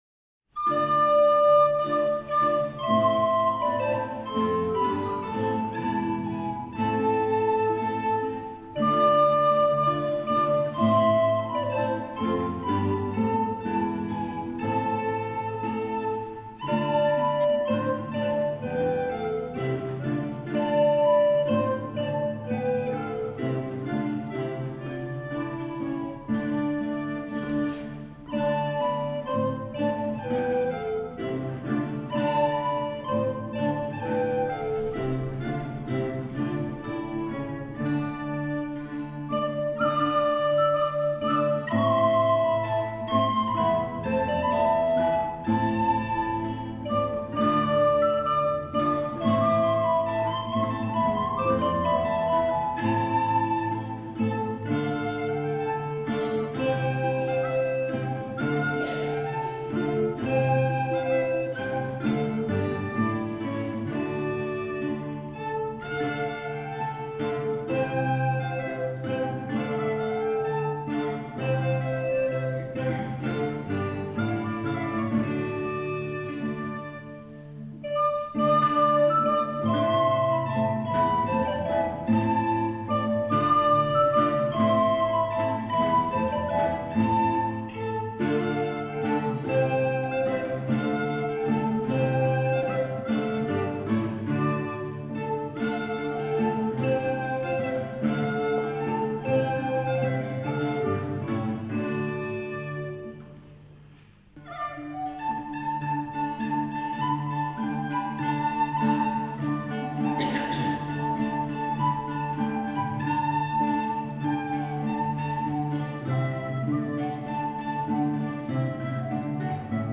Instrumentale Tanz- und Tafelmusik